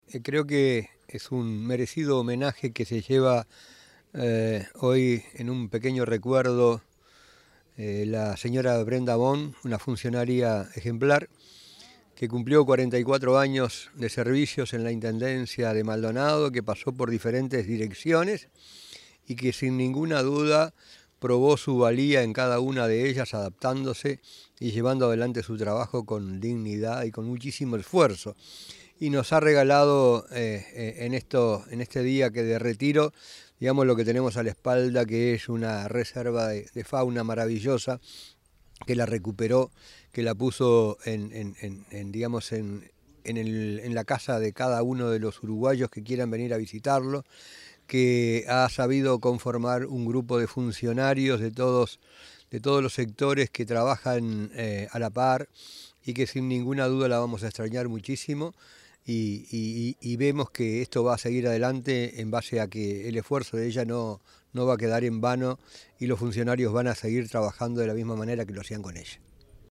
El reconocimiento, que se desarrolló este viernes 31 de julio, consistió en la entrega de una placa y flores, al tiempo que fue acompañada por autoridades y compañeros.
NOTAS DE AUDIO
Jesús Bentancur – Intendente de Maldonado